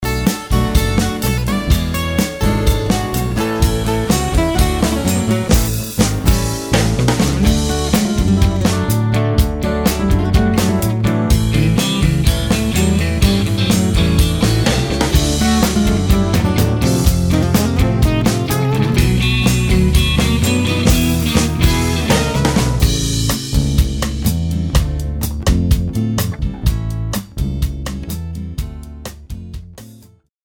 Так вот, почти во всех демках на малом пулеметит посильнее чем в SSD... пулемета совсем нет п.с. все демки звучат пластмассово... п.п.с и самое отвратительное всего пару...